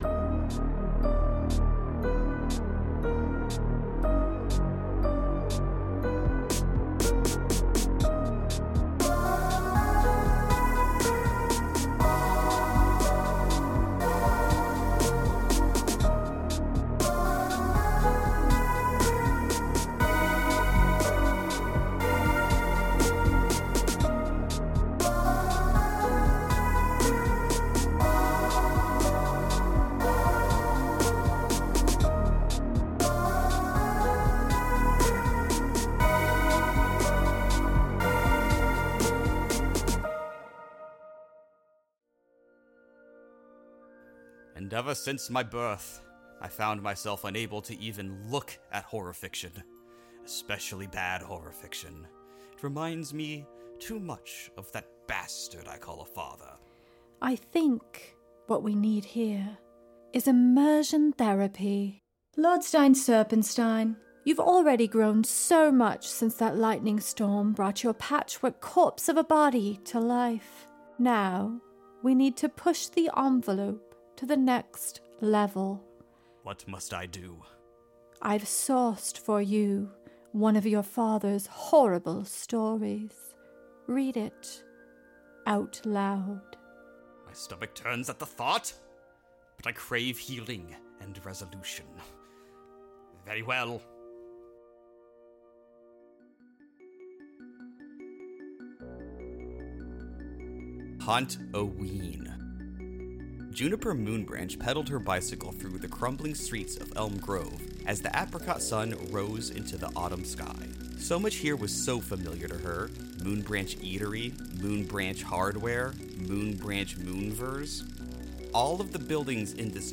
Just listen to this spooky novelty Halloween story.